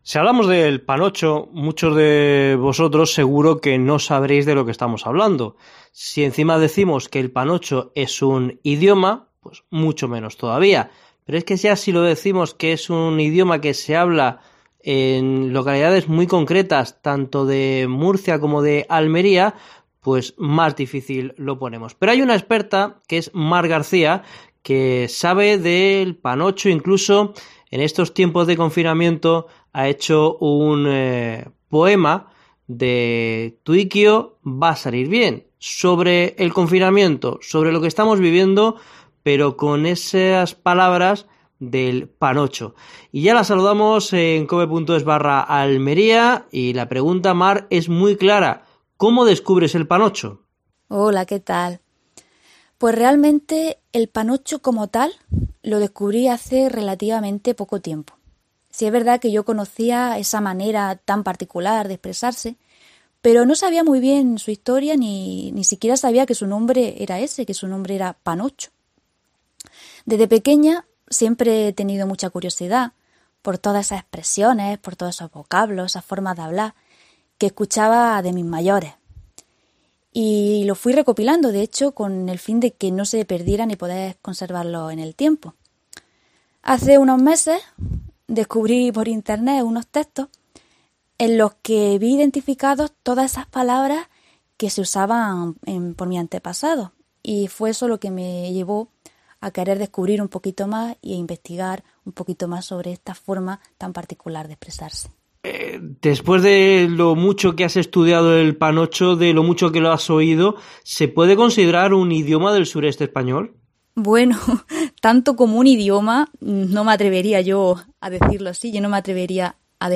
Tal y como reconoce en COPE Almería en la entrevista que podéis escuchar en el audio que acompaña este artículo.